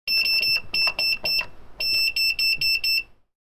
Futuristic Computer Console Beeps – Sci-Fi Sound Effect
Beep-noise-sound-effect.mp3